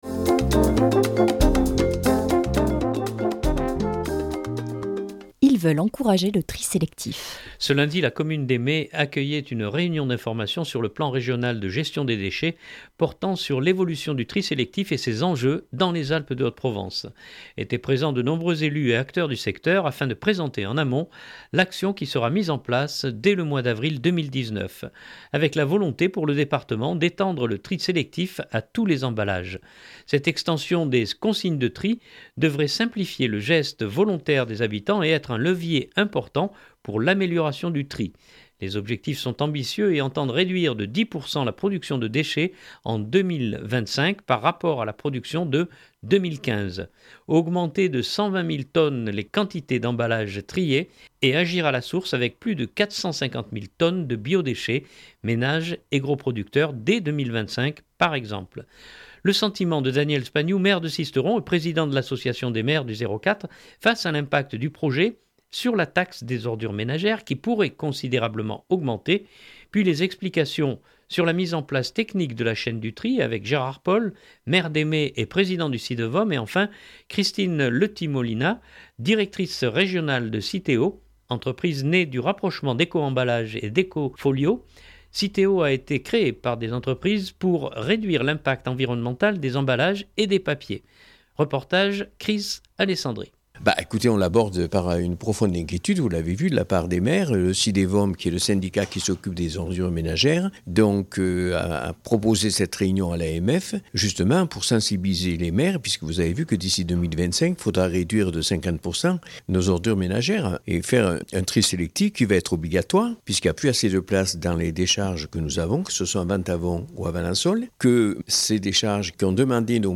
JR-2019-01-30 - Les Mées-Le Tri sélectif.mp3 (18.98 Mo) Ce lundi, la commune des Mées accueillait une réunion d’information sur le plan régional de gestion des déchets, portant sur l’évolution du tri sélectif et ses enjeux dans les Alpes de Haute-Provence.
Le sentiment de Daniel Spagnou maire de Sisteron et Président de l’association des maires du 04 face à l’impact du projet sur la taxe des ordures ménagères qui pourrait considérablement augmenter.